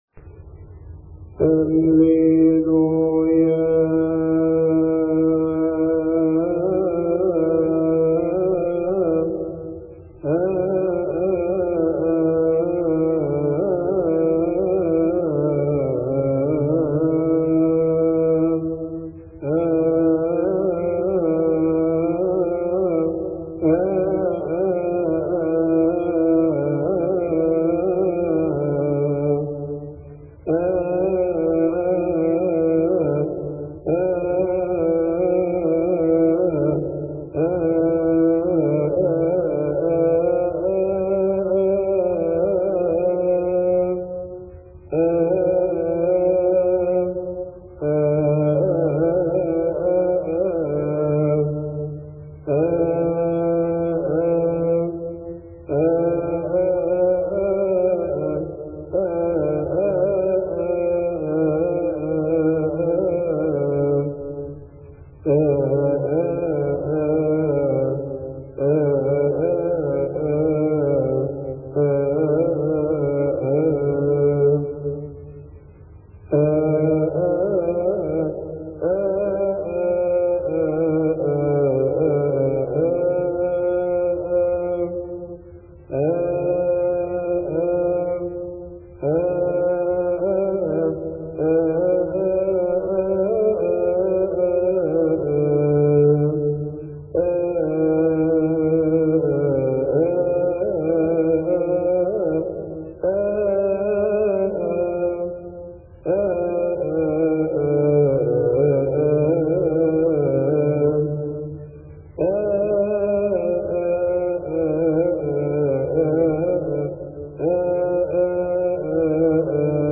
لحن الليلويا